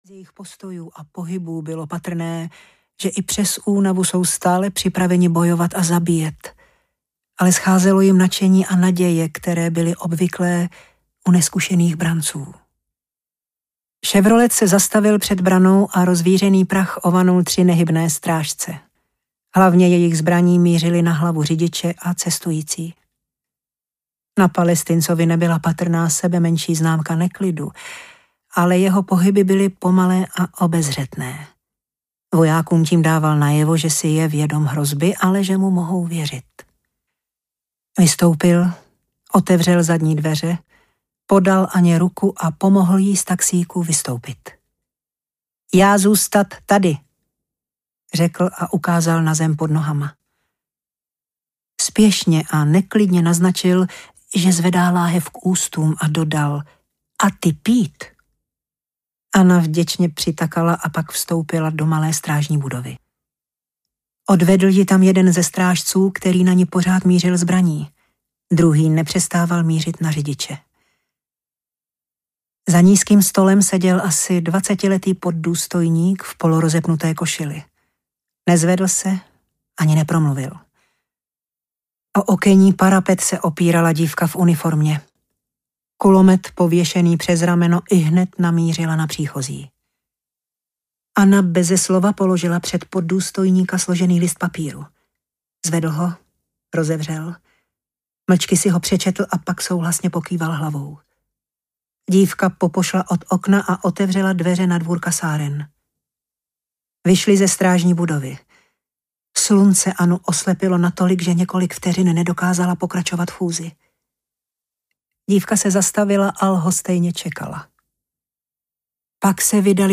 Elizino tajemství audiokniha
Ukázka z knihy